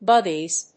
/ˈbʌgiz(米国英語), ˈbʌgi:z(英国英語)/